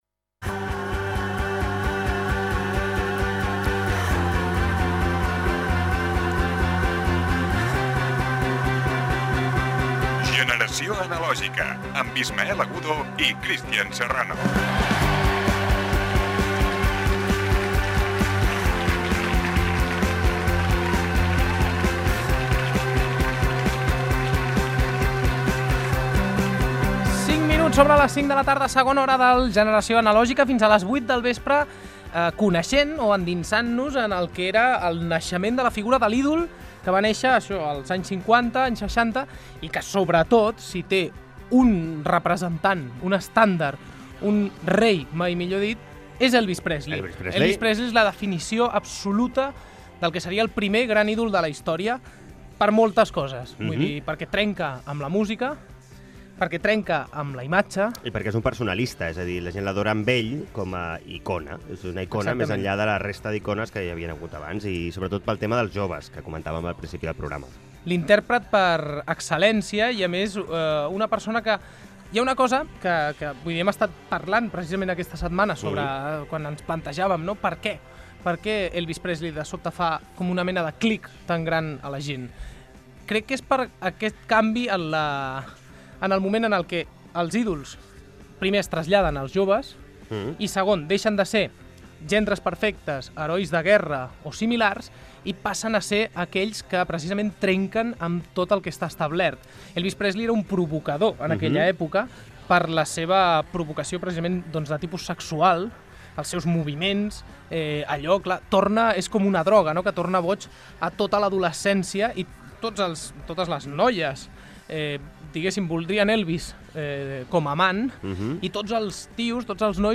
Identificació del programa, inici de la segona hora. La figura del cantant Elvis Presley.
Musical
FM